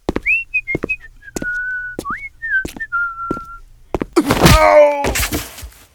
uppercut.wav